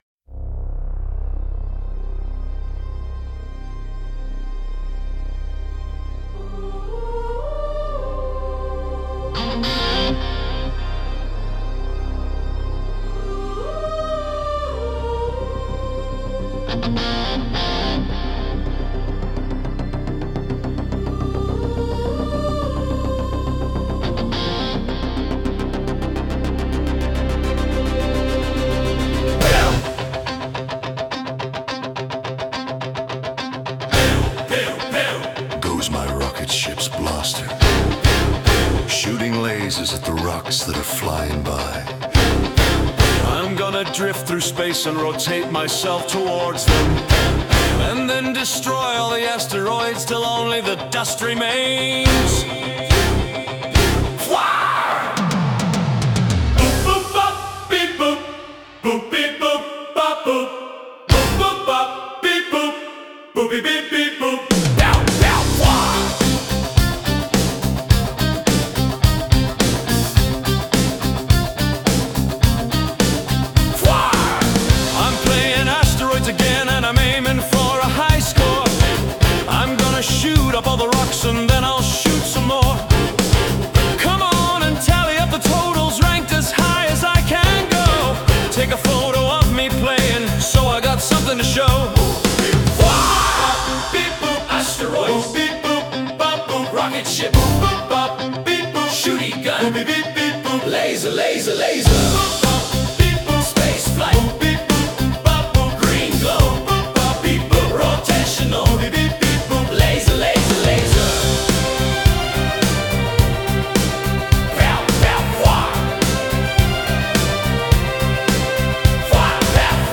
Lyrics : By me
Sung by Suno
Electronic_Rocks-3_mp3.mp3